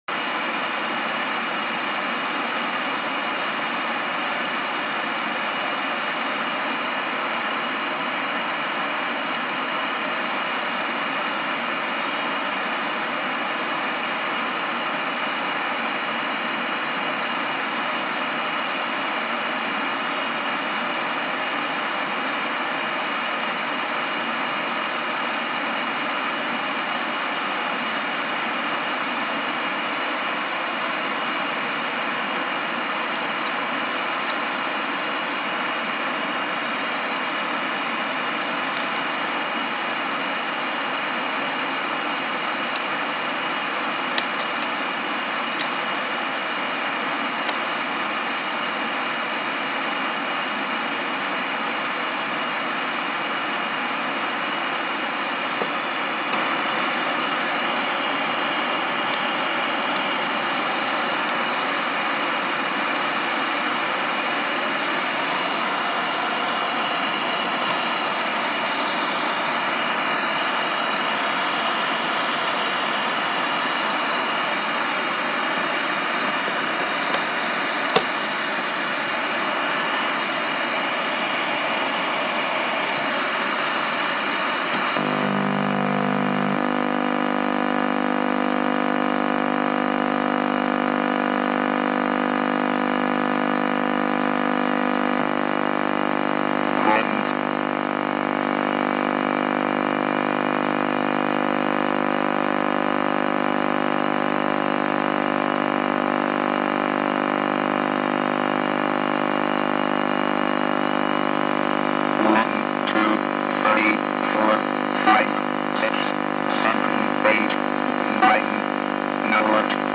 Ever wondered how Text to Speech sounds sound effects free download
Ever wondered how Text-to-Speech sounds in 1984 on a 1982 computer?